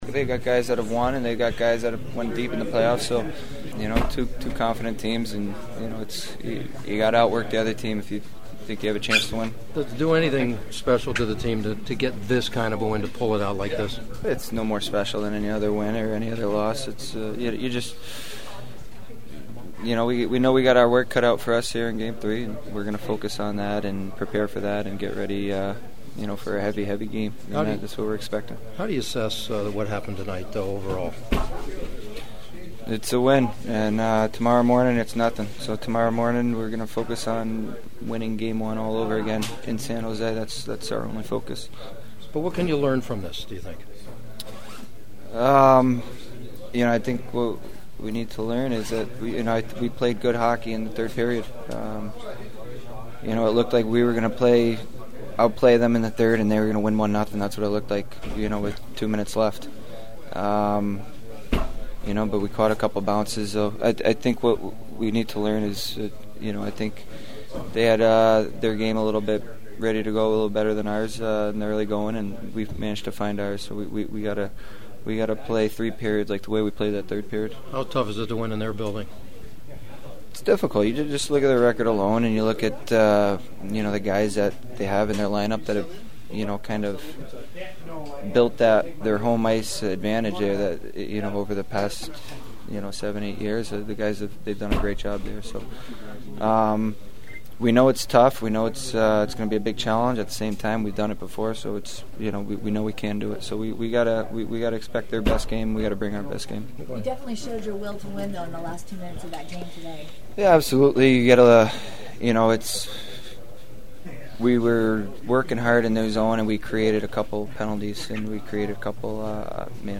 The sounds of the game from the locker room tell a story of a team that’s feeling relieved as much as joy after one of the great playoff wins in their club’s history.
Kings goalie Jonathan Quick: